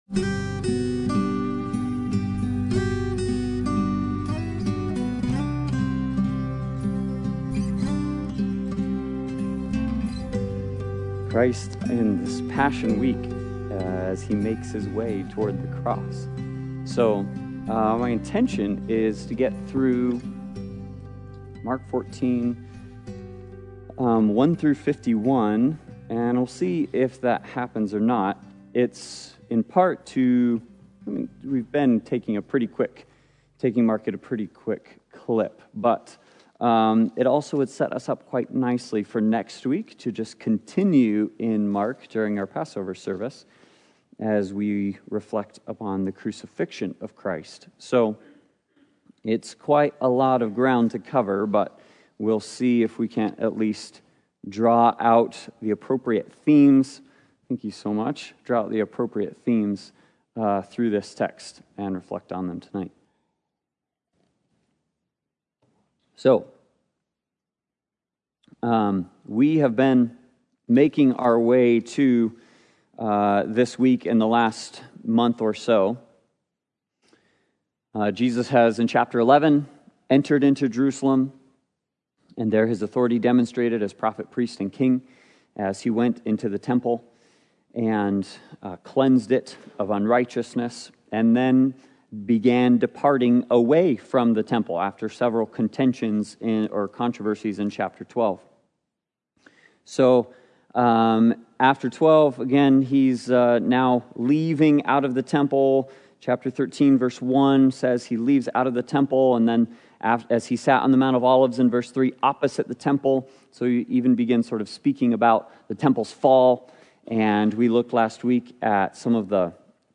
Passage: Mark 14:1-51 Service Type: Sunday Bible Study « Helmet & Sword